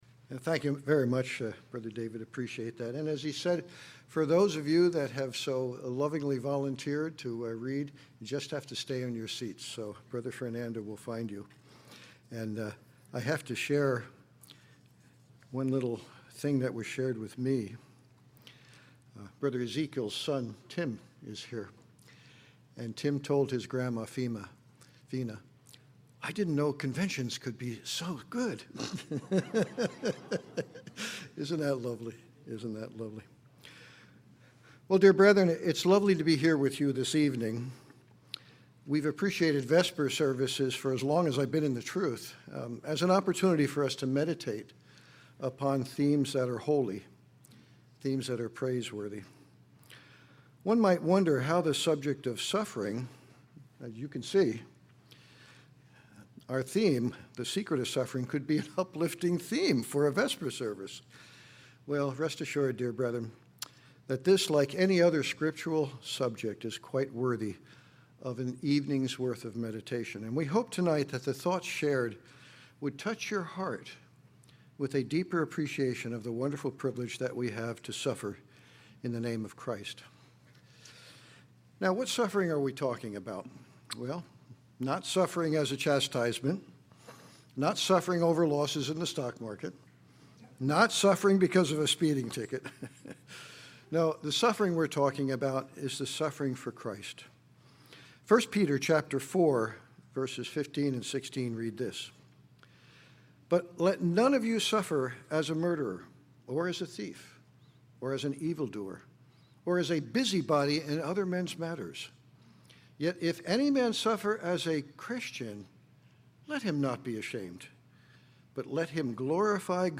Series: 2026 Wilmington Convention